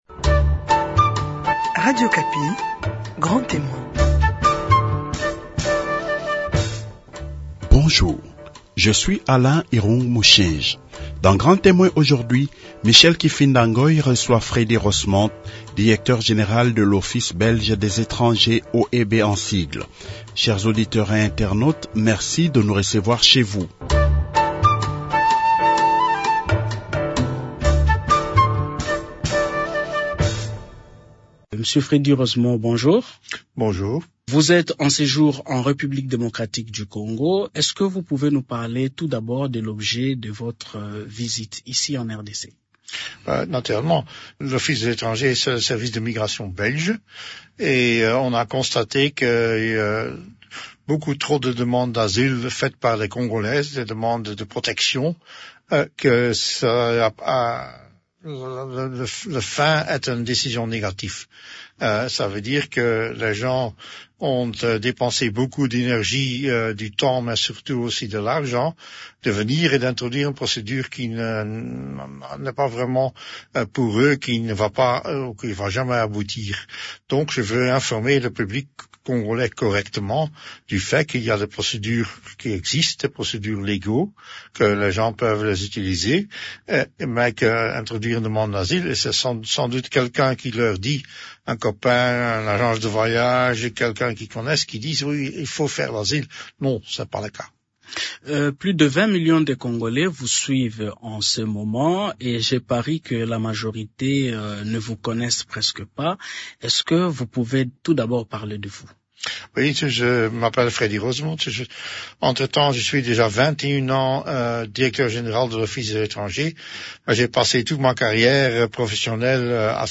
Grand Témoin reçoit Freddy Roosemont, Directeur général de l’Office belge des étranger (OEB).